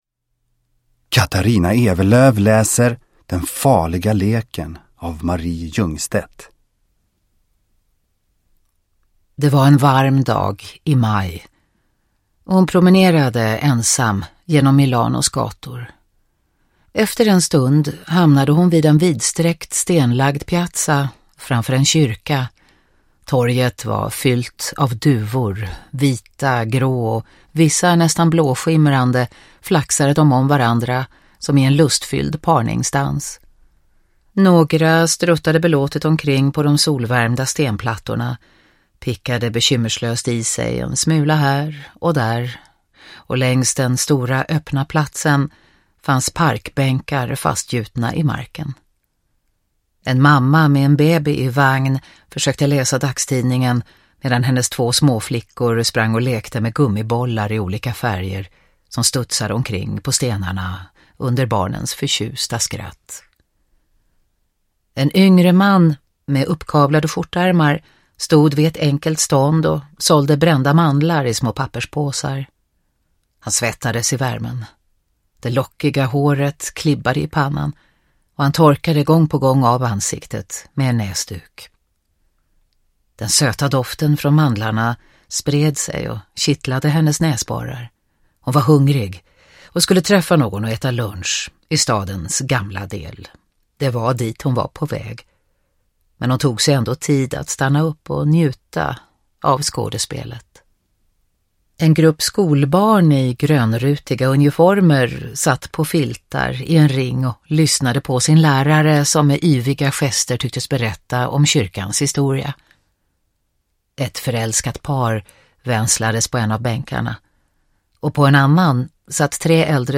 Den farliga leken – Ljudbok – Laddas ner